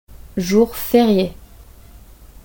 If you ever hear or see a sign with the French word férié ” /Fey-REE-Yey/, it means it’s a French public holiday, aka bank holiday,
Click below to hear how it’s pronounced.
pronunciation_fr_jour_ferie.mp3